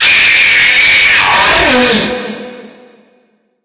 PLAY FNAF Ennard Jumpscare
ennard-jumpscare.mp3